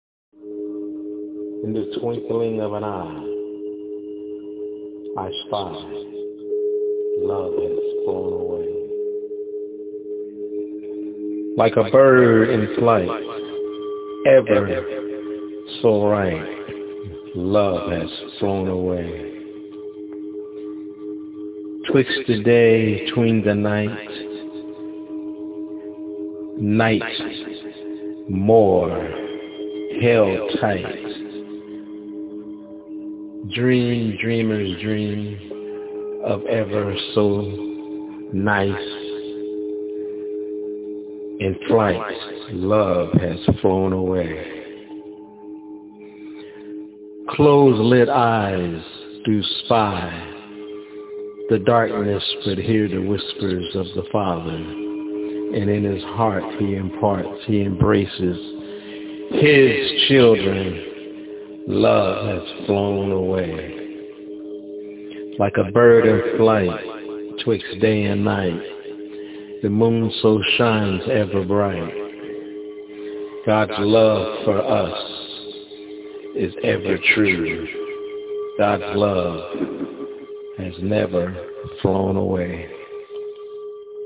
Love Has Flown Away- My Spokenword